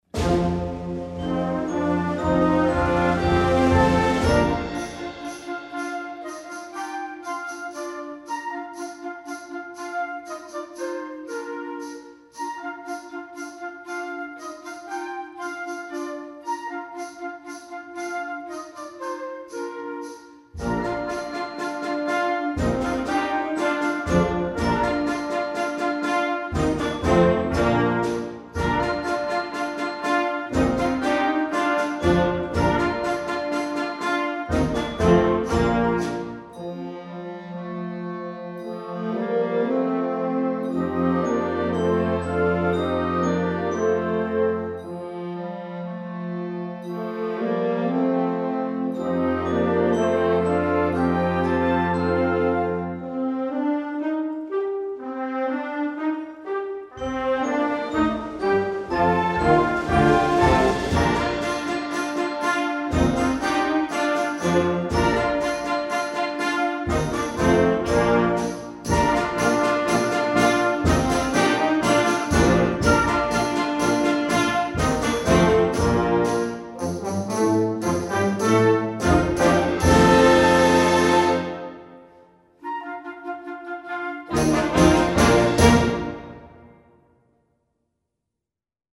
classical, pop, instructional, children